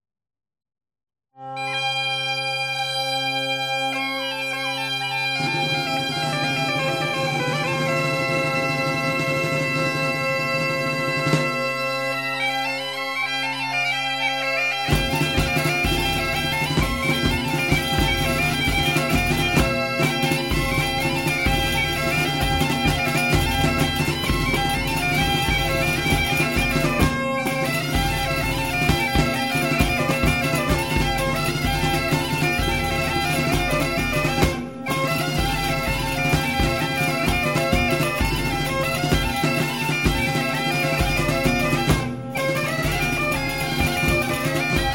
Tradicional